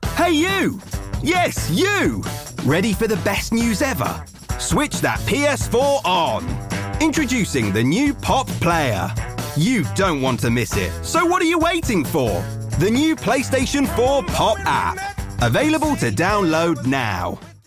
Male
Yng Adult (18-29), Adult (30-50)
I have a contemporary, energetic and youthful voice. My native accent is South East London/General RP but I am able to record in various accents.
Television Spots
Kids Tv Spot
All our voice actors have professional broadcast quality recording studios.